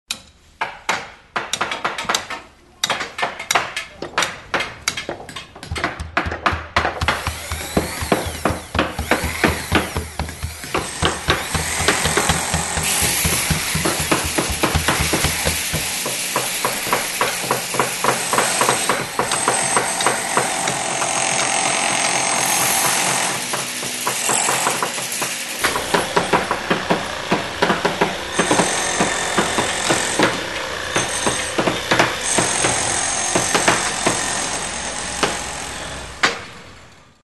Звуки стройки, ремонта
Здесь вы найдете шумы инструментов, техники и обстановки строительной площадки.
Всё стучит гудит работает сразу